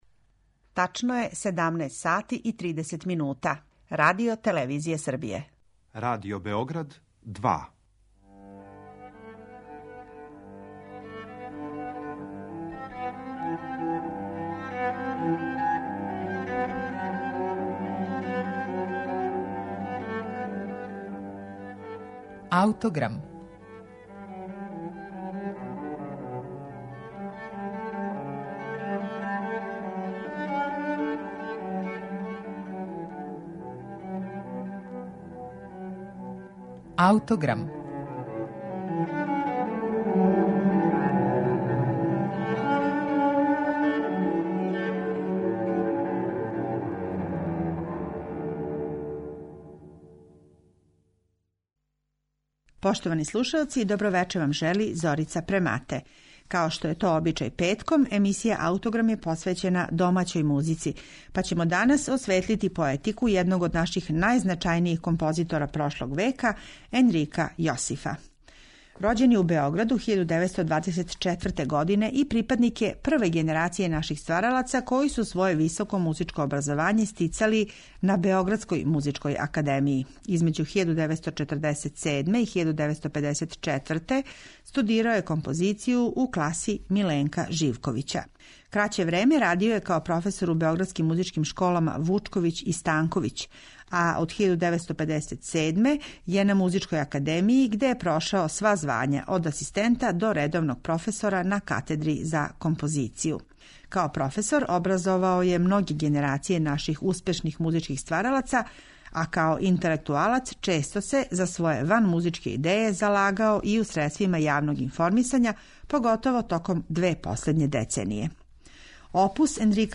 Енрико Јосиф: Необарокне свите за симфонијски оркестар